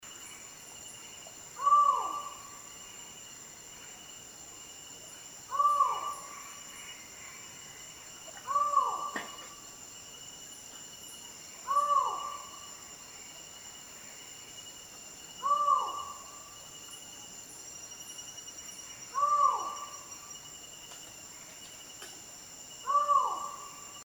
Collared Forest Falcon (Micrastur semitorquatus)
050414-halcon-montes-grande.MP3
Location or protected area: Reserva Privada y Ecolodge Surucuá
Condition: Wild
Certainty: Recorded vocal